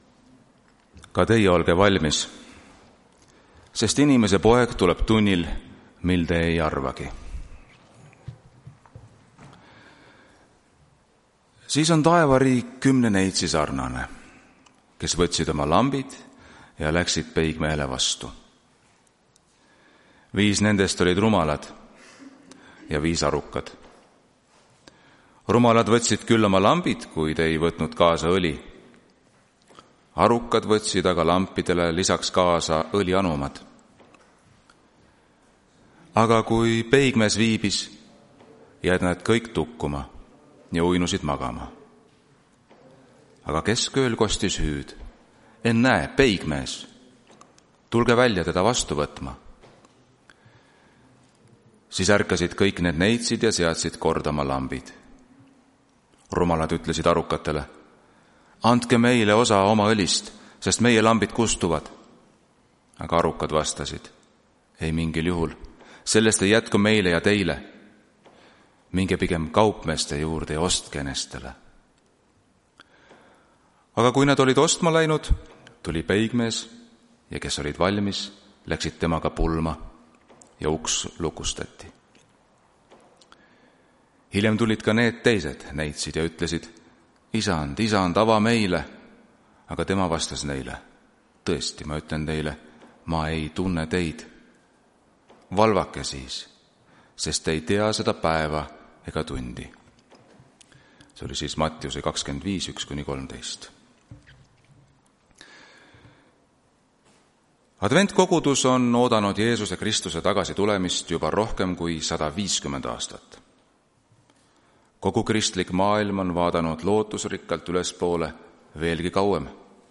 Tartu adventkoguduse 26.07.2025 hommikuse teenistuse jutluse helisalvestis.
Jutlused